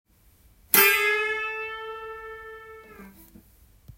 ④のチョーキングは、オクターブチョーキングと言います。
この奏法も２本の弦を一緒に弾きますが、２弦のみをチョーキングして
１弦はチョーキングしないで普通に弾きます。